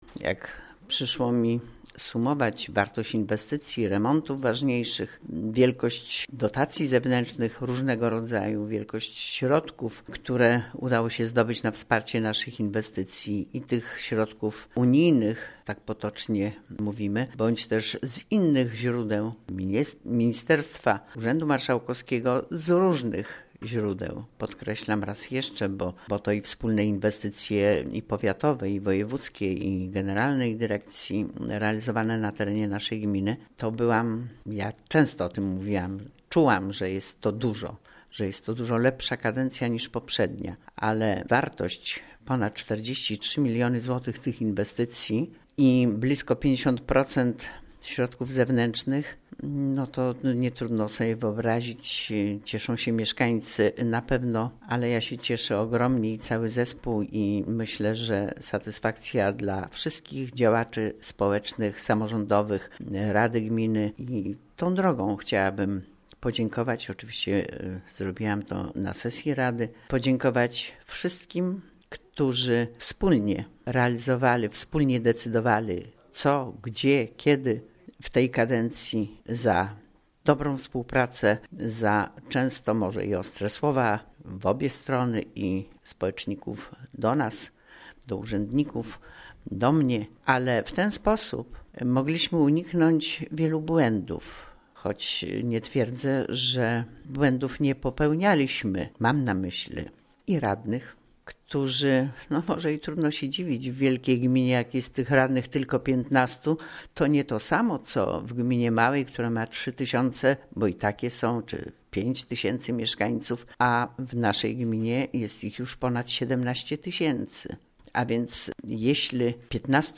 O tym jaka by�a to kadencja informuje: W�jt Gminy �uk�w Kazimiera Go�awska